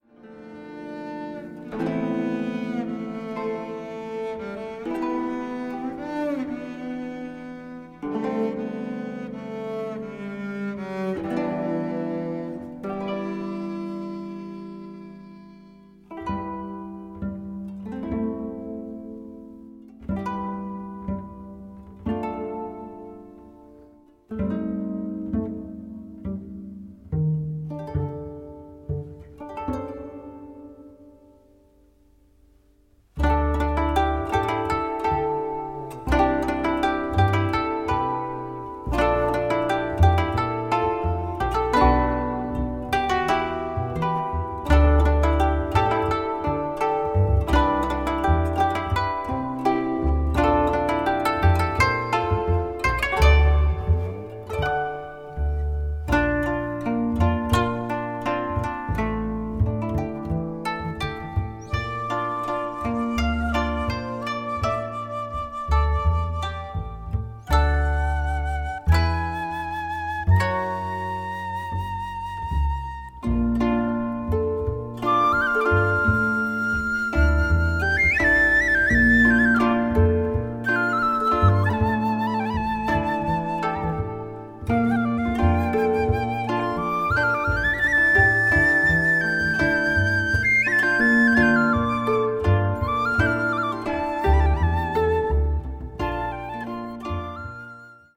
20 string Koto